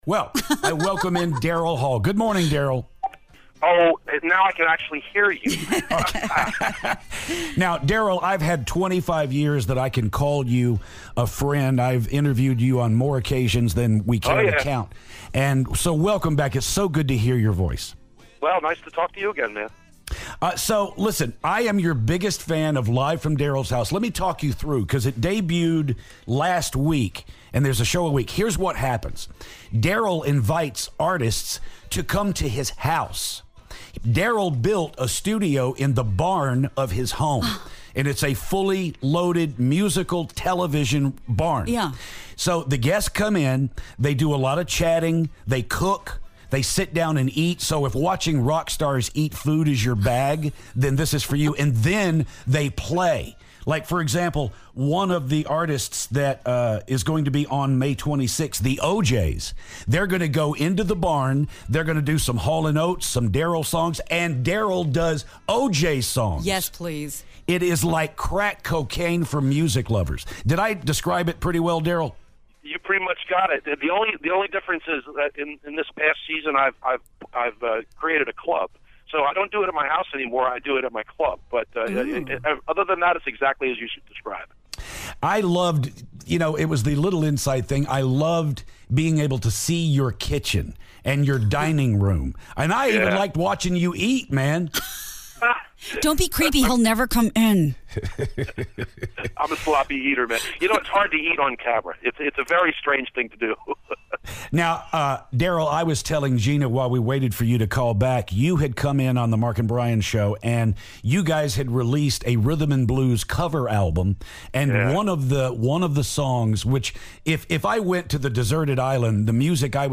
Daryl Hall calls to talk about his upcoming season of "Live From Daryl's House."